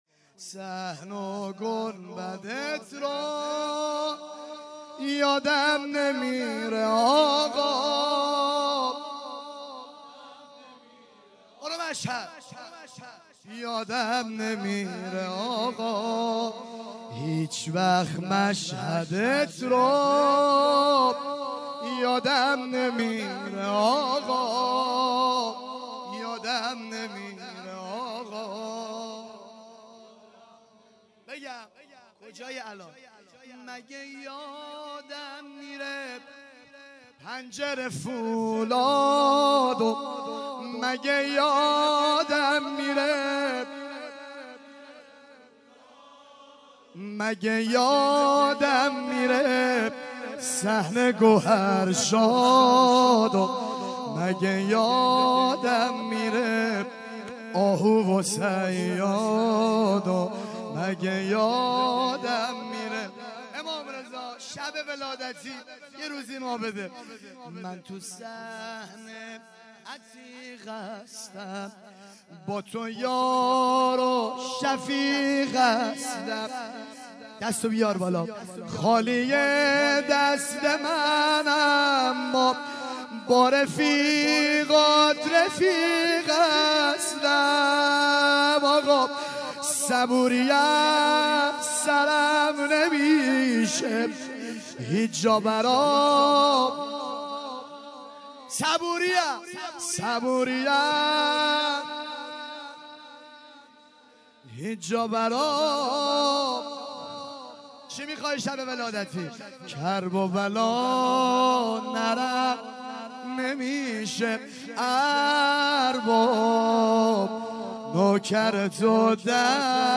3- صحن و گنبدت رو - زمزمه